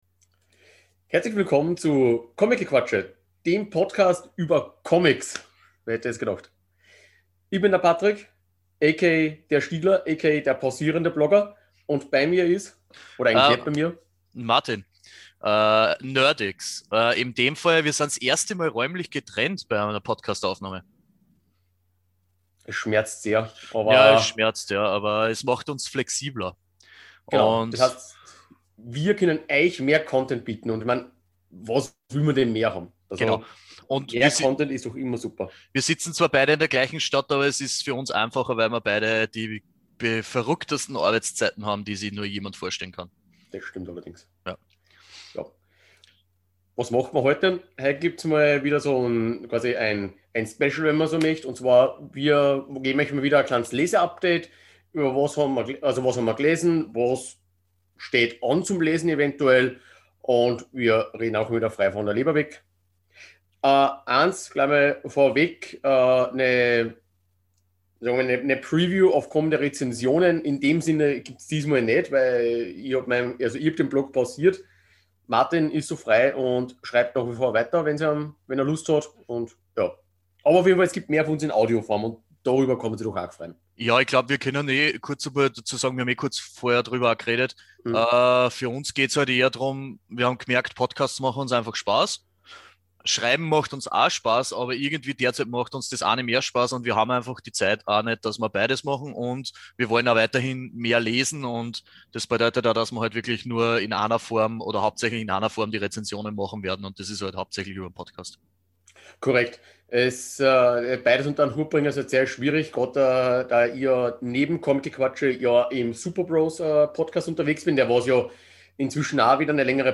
Beschreibung vor 4 Jahren Wieder ein lockeres drauflosgequatsche von uns beiden! Inzwischen nehmen wir aufgrund der Corona-Situation auch räumlich getrennt voneinander auf!